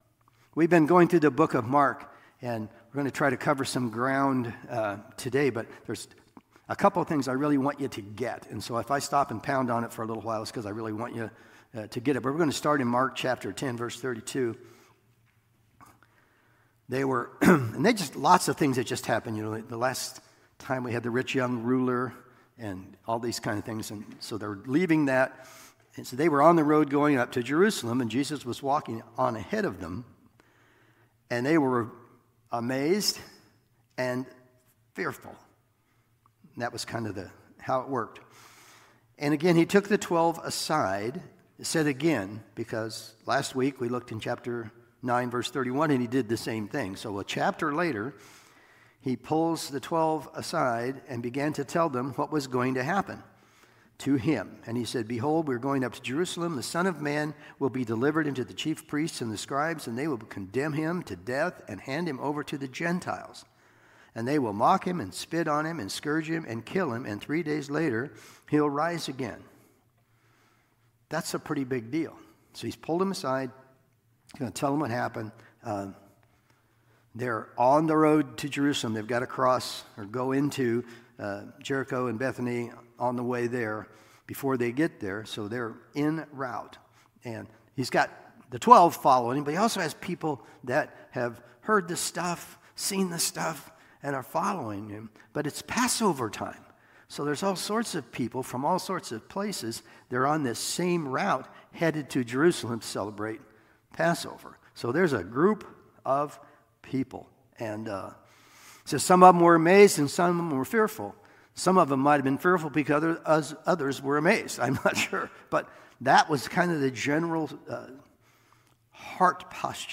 Sunday Morning Sermon Download Files Notes